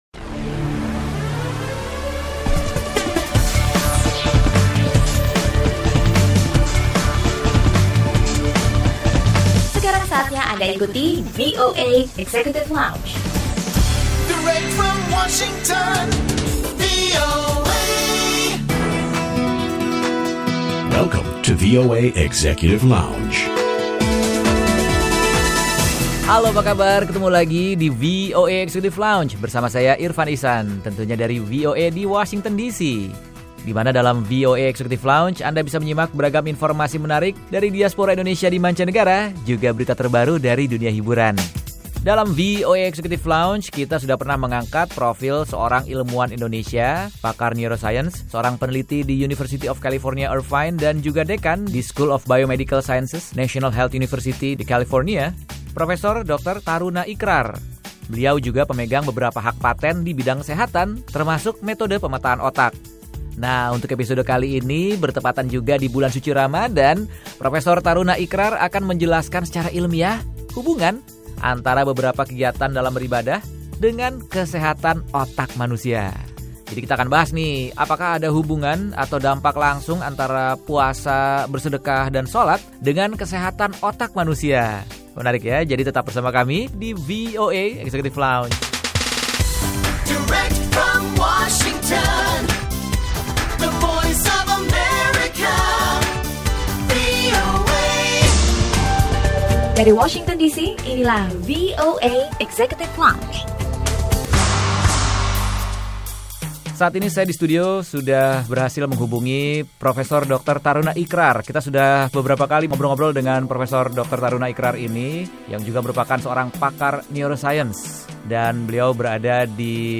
Obrolan bersama Taruna Ikrar, seorang profesor, peneliti, pakar Neurosains dan Dekan di School of Biomedical Sciences, National Health University, California, mengenai dampak melakukan ibadah Puasa & Bersedekah terhadap kesehatan otak manusia.